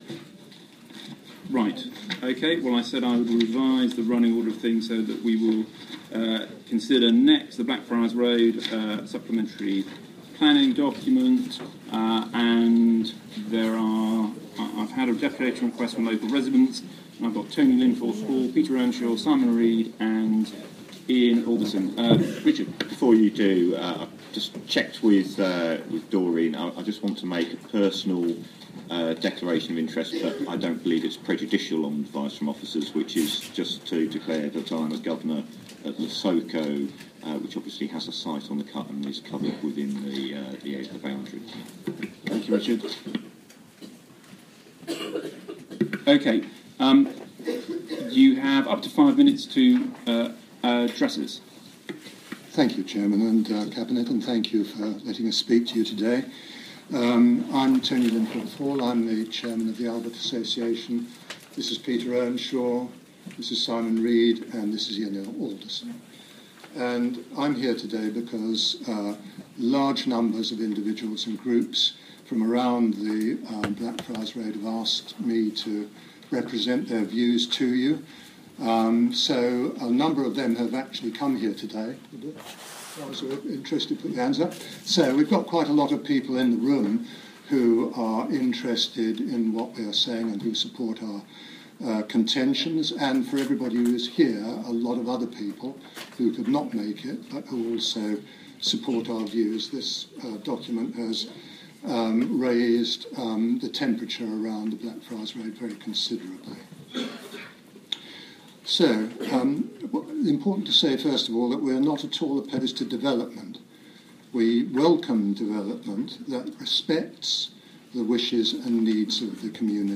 Blackfriars Road SPD: residents' deputation to cabinet